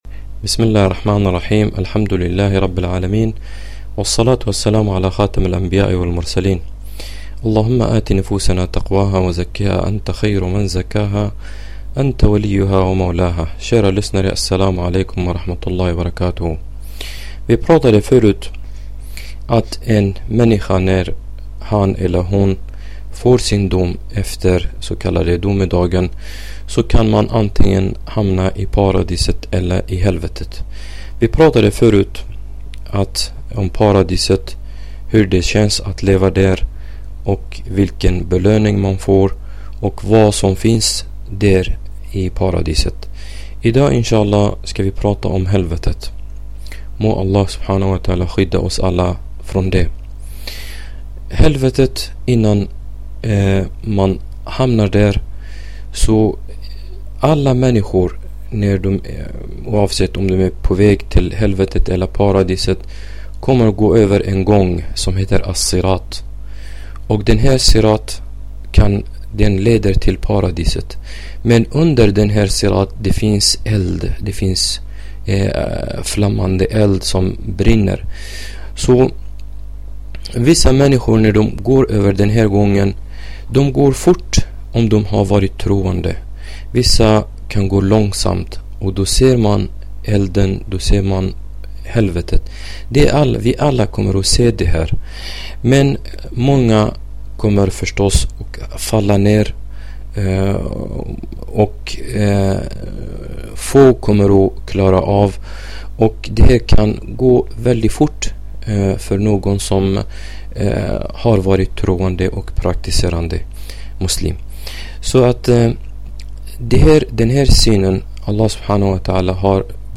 Användarbetyg: / 12 Dåligt Bra Ett föredrag som beskriver helvetet...